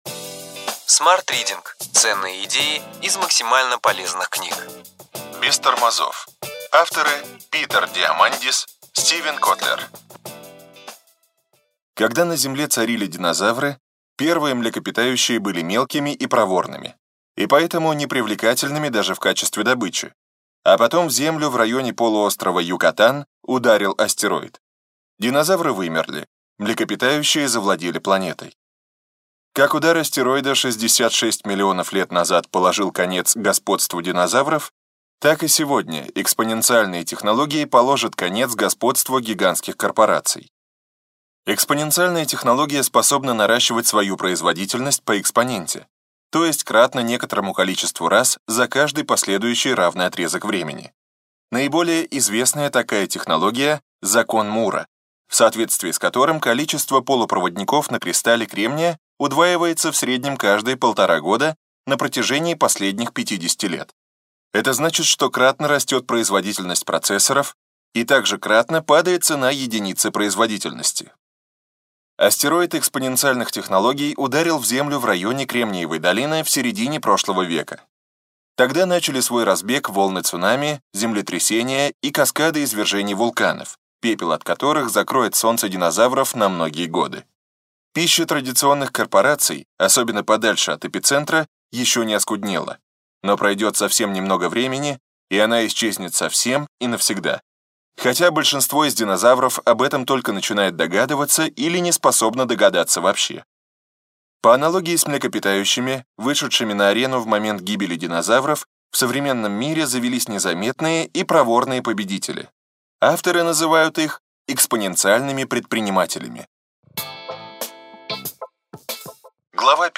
Аудиокнига Ключевые идеи книги: Без тормозов. Питер Диамандис, Стивен Котлер | Библиотека аудиокниг